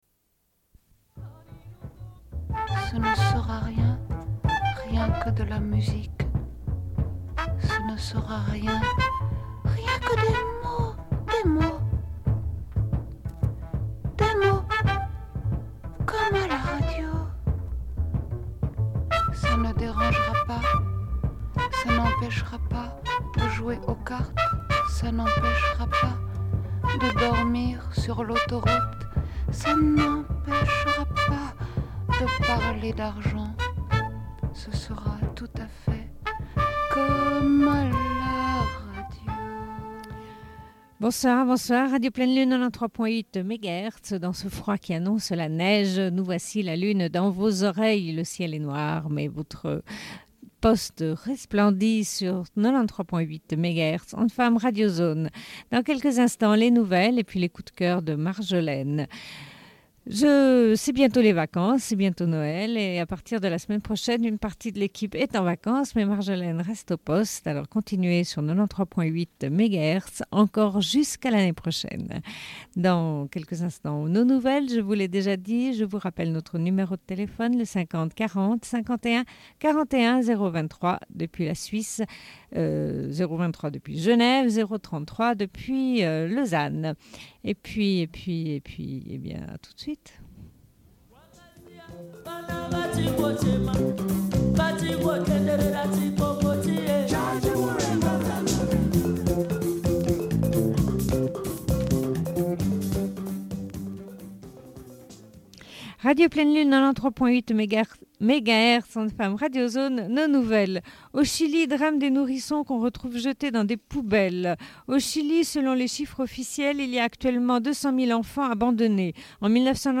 Une cassette audio, face B29:35